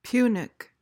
PRONUNCIATION: (PYOO-nik) MEANING: adjective: 1.